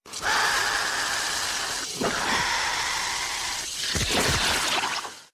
dinosaur-flying.mp3